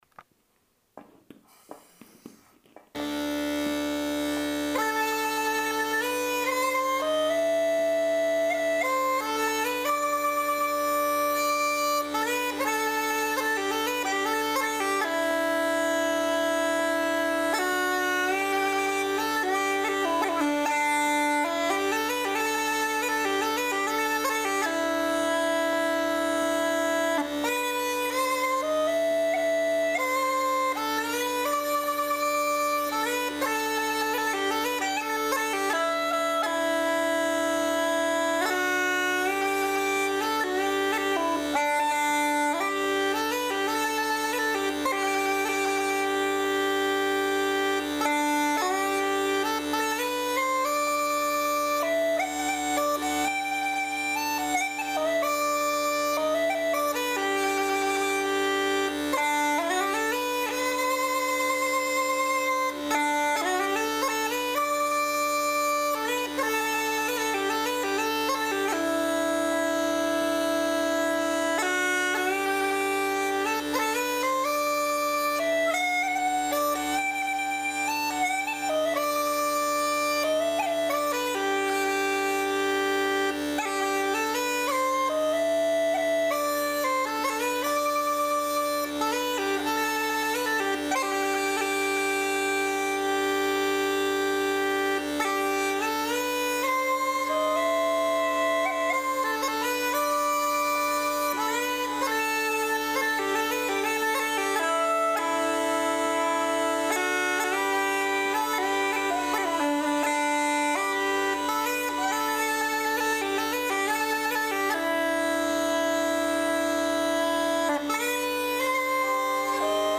Anyhoo, I’m in a somewhat reflective state of mind today, so here’s a swipe at playing an air, The Green Fields of America.
Things to work on: I’m still having trouble with those lower reg chords — some pressure management issues, my baritone D honks unless you hit it just right, and all the texture goes right out of my chanter playing whenever I hit a chord (can manage to keep the texture with a single reg note, at least).
…I’m a perpetually aspiring uilleann piper.